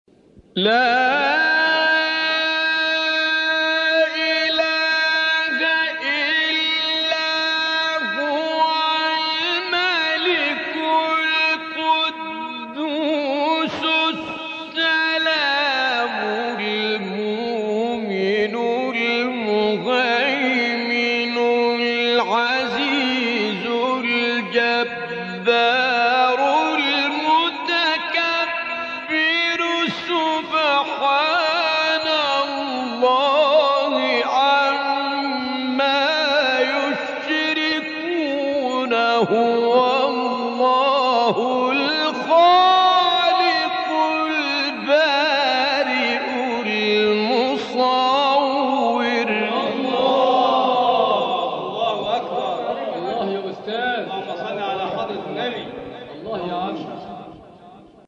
گروه فعالیت‌های قرآنی: مقاطعی صوتی از قاریان برجسته جهان اسلام که در مقام رست اجرا شده‌اند، ارائه می‌شود.
مقام رست